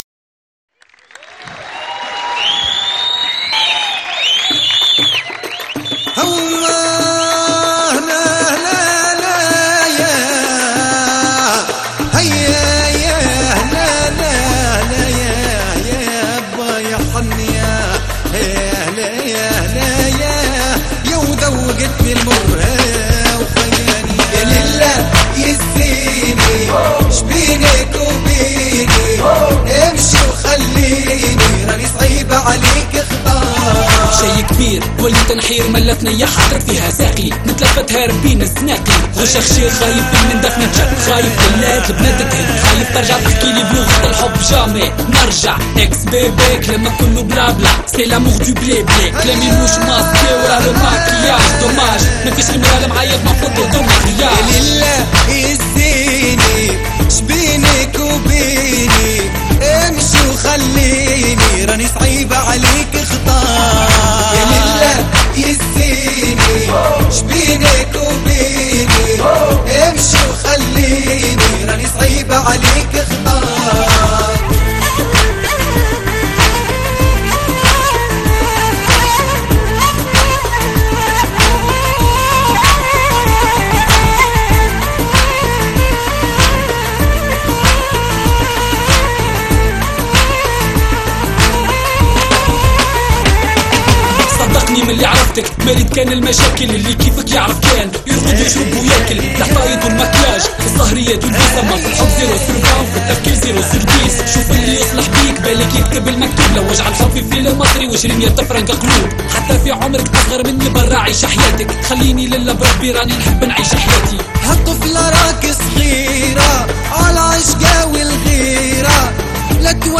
Bienvenue au site des amateurs de Mezoued Tunisien